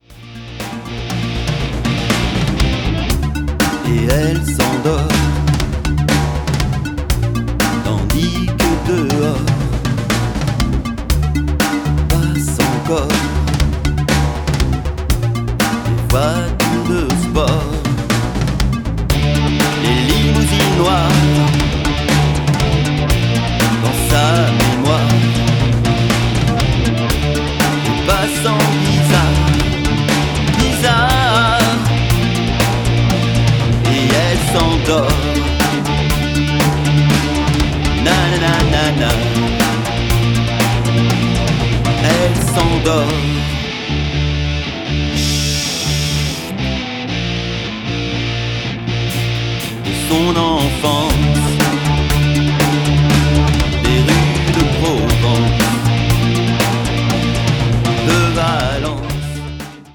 mi-rock, mi-electro